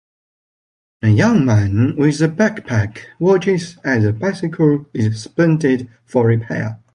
Pronúnciase como (IPA) /ˈwɑt͡ʃɪz/